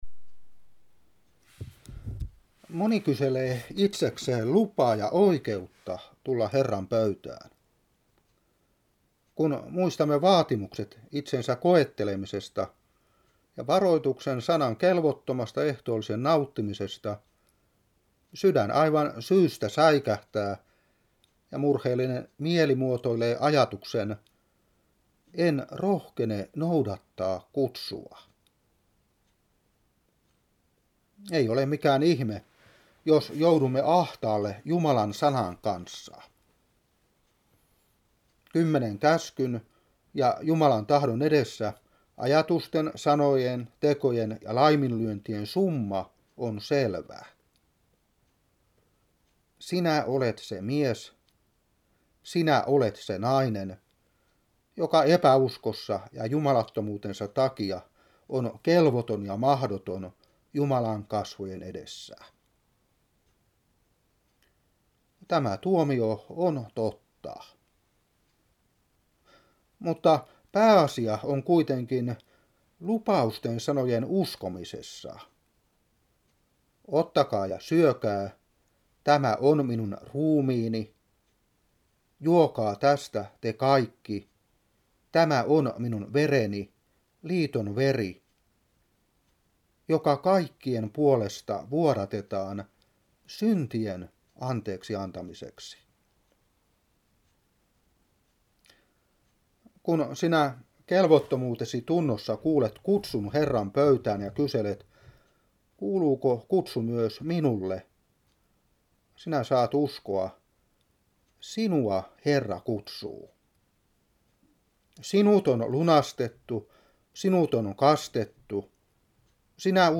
Opetuspuhe 1996-2. 1.Kor.11:28.